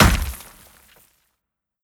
Hit_Concrete 04.wav